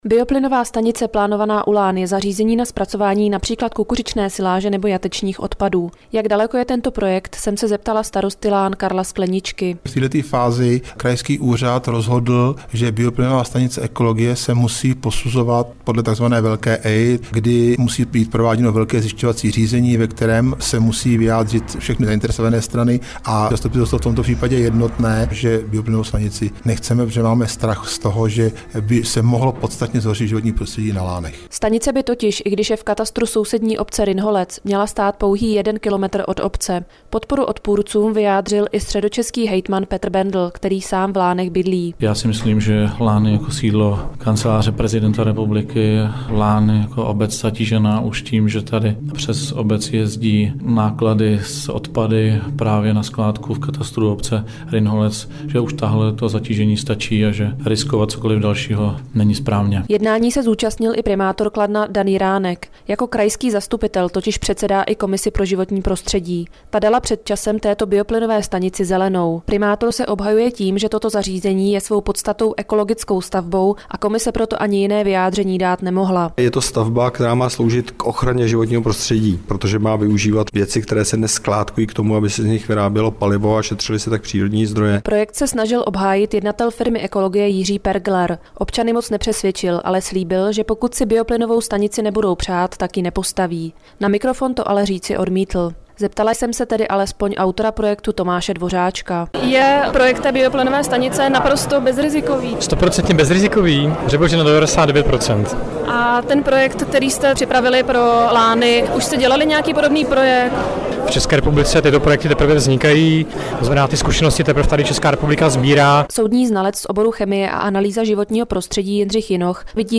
Reportáž o bioplynové stanici, kterou odvysílal Český rozhlas Region dne 20.10.2007
U obce Lány na Kladensku by měla vzniknout bioplynová stanice. Záměr společnosti Ekologie s.r.o. se ale setkal s masivním odporem místních obyvatel. Na 200 se jich sešlo na úterním jednání obecního zastupitelstva, které jednohlasně vyjádřilo svůj nesouhlas se stavbou.